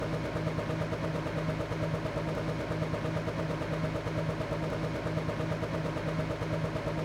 new-wheel-sound.m4a